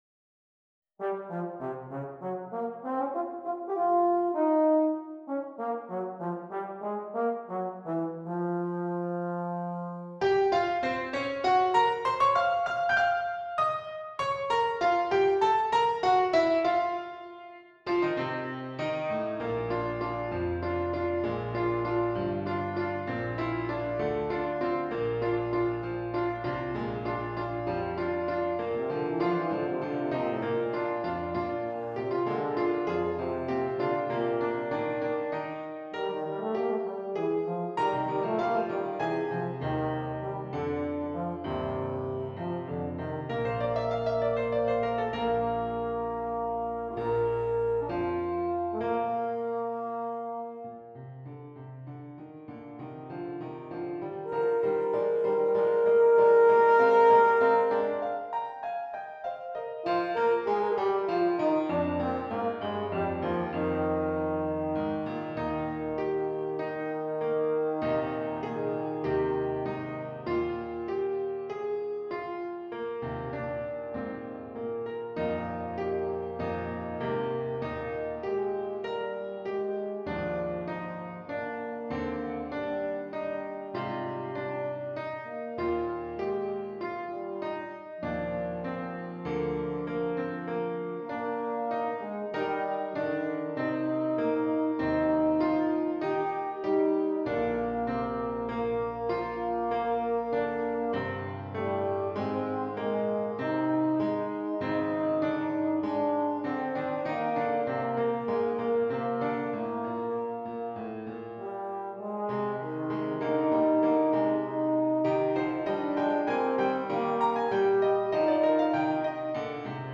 Difficulty: Intermediate